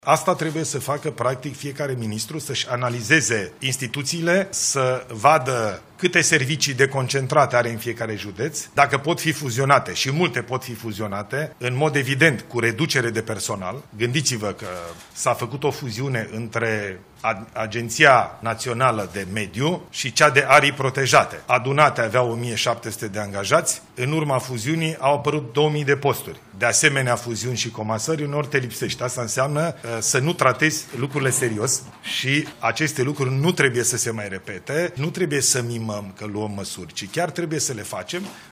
Ilie Bolojan, premierul României: „Nu trebuie să mimăm că luăm măsuri, ci chiar trebuie să le facem”